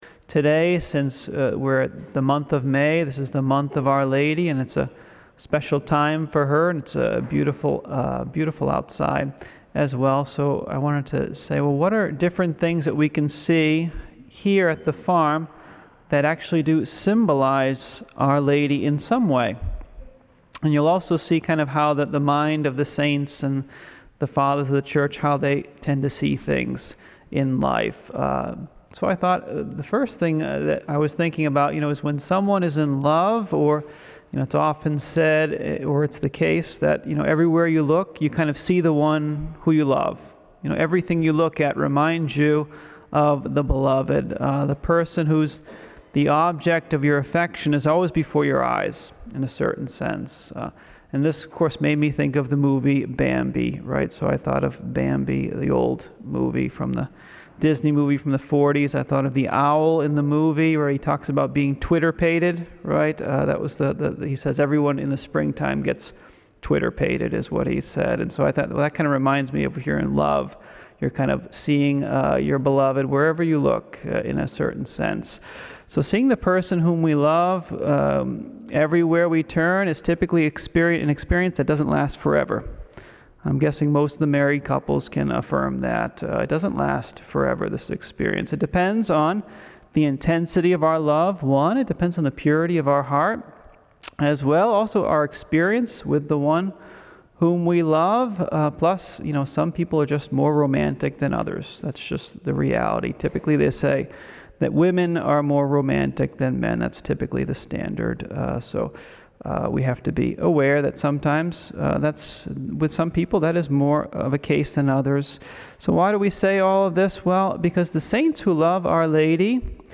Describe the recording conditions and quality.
At the 2021 Spring Marian Day of Reflection on May 1st at the Mother of the Redeemer Retreat Center in Bloomington, Indiana (that is commonly called "The Farm")